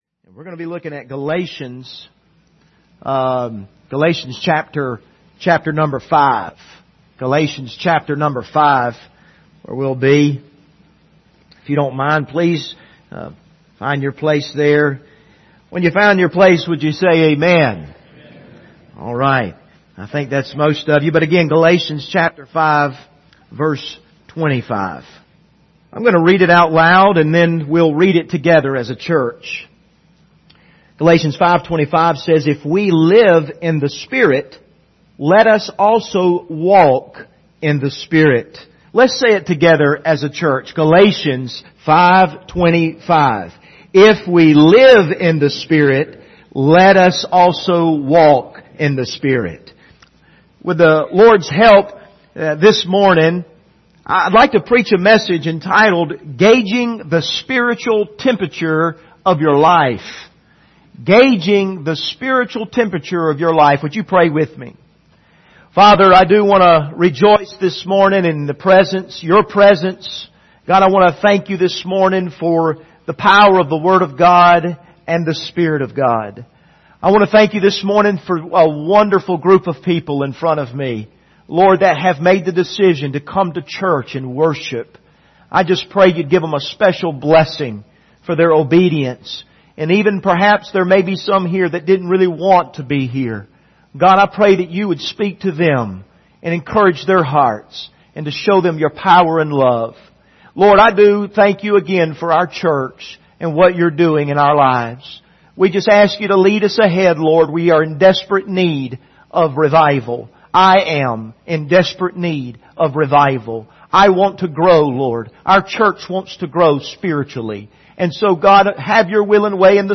Galations 5:25 Service Type: Sunday Morning « Facing the Storms of Life How Do You Plead?